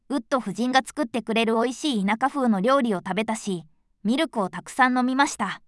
voicevox-voice-corpus / ita-corpus /No.7_ノーマル /EMOTION100_049.wav